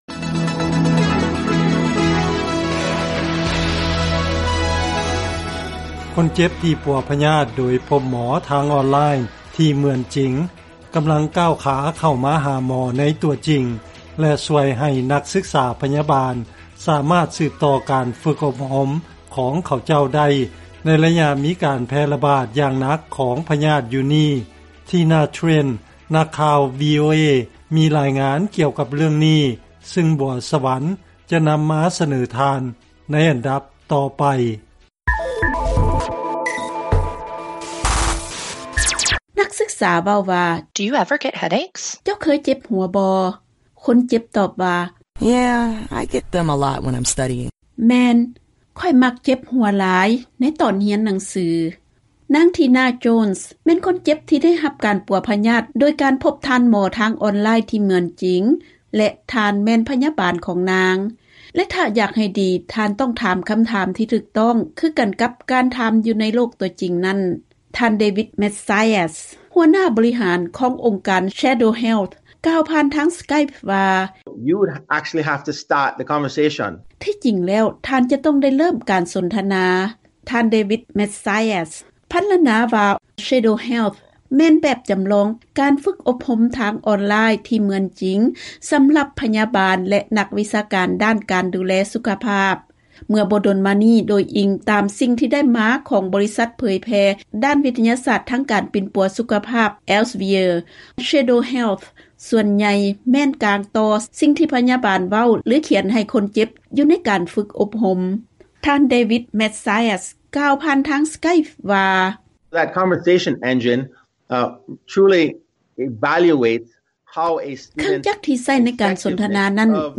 ເຊີນຟັງລາຍງານກ່ຽວກັບການໃຊ້ເຄື່ອງຈໍາລອງເໝືອນຈິງເພື່ອຝຶກອົບຮົມພະຍາບານ